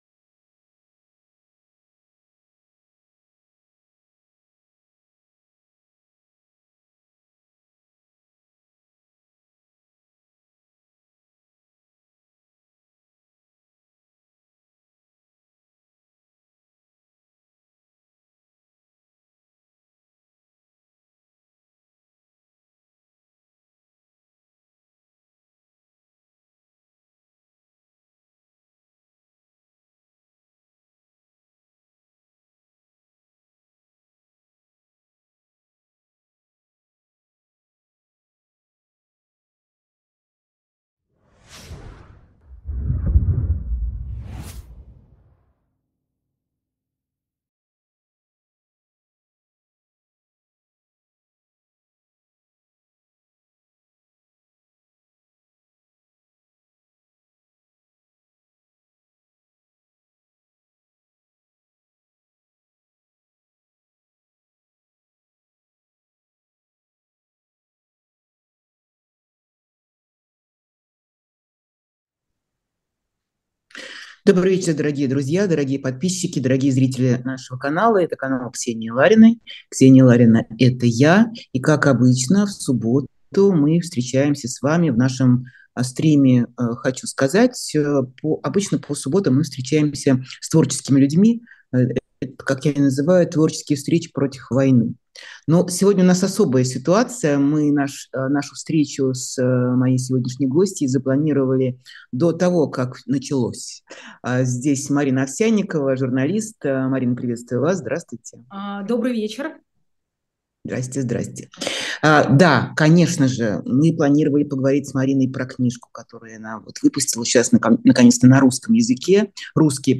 Ксения Ларина — это я. И как обычно, в субботу мы встречаемся с вами в нашем стриме «Хочу сказать».